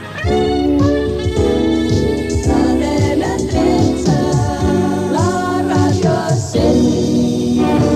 3d0e0fd8b9259f4b7509b2768b80d2c92f1f0abb.mp3 Títol Cadena 13 Emissora Cadena 13 Barcelona Cadena Cadena 13 Titularitat Privada nacional Descripció Identificació de la cadena radiofònica.